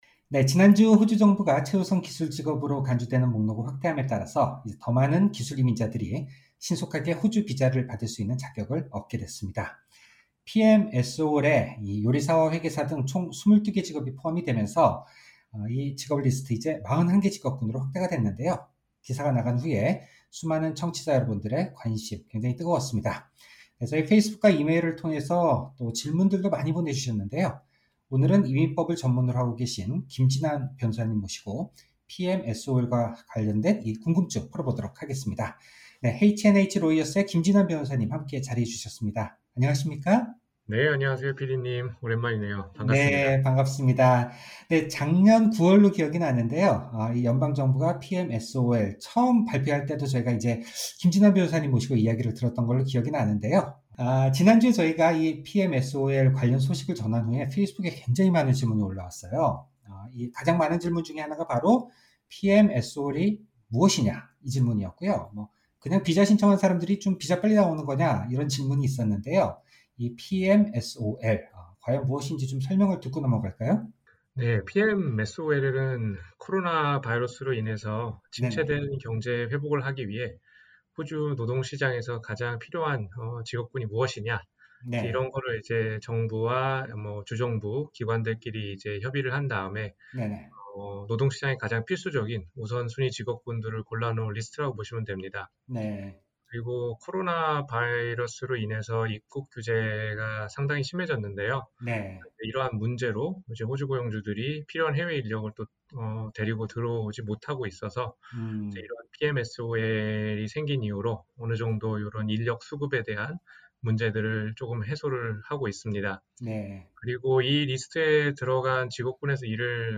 pmsol_economy_briefing_final_0207.mp3